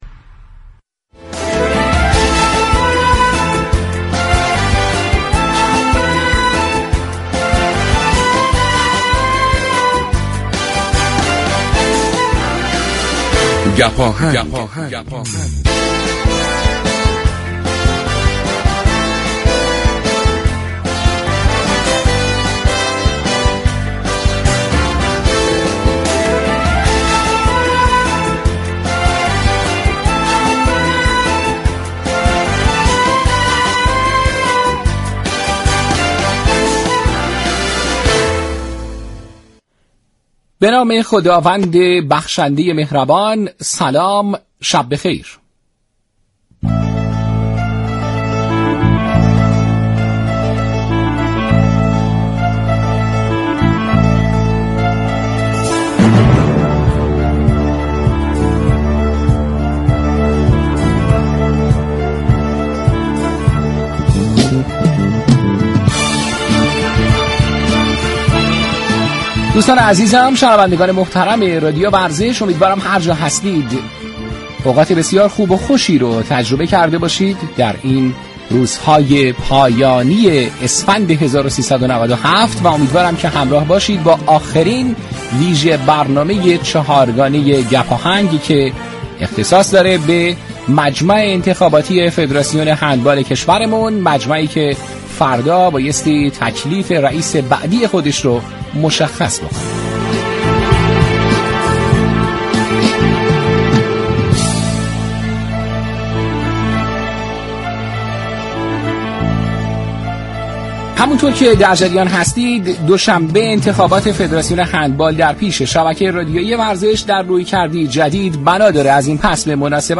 فایل صوتی مناظره چهارم نامزدهای انتخابات ریاست فدراسیون هندبال از رادیو ورزش
چهارمین مناظره نامزدهای انتخابات ریاست فدراسیون هندبال، یكشنبه 19 اسفند از ساعت 22 تا 24 به صورت زنده و مستقیم از برنامه چهارگانه گپ آهنگ كه اختصاص به انتخابات فدراسیون هندبال دارد؛ پخش می شود.